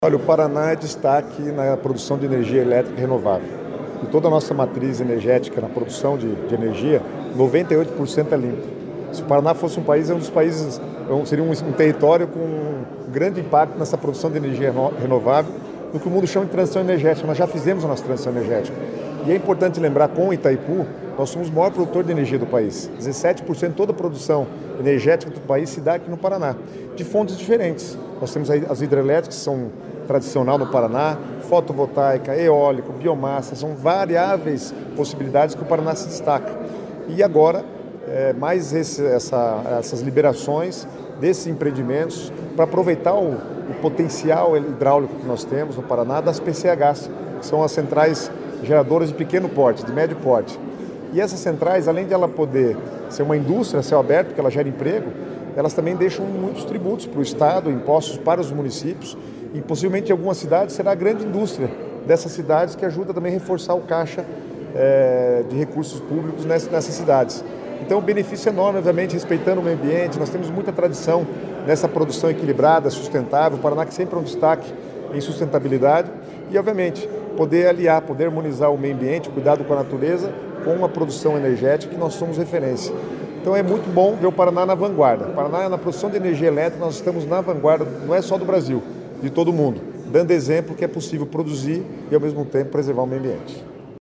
Sonora do secretário das Cidades, Guto Silva, sobre a construção de 11 novas PCHs no Paraná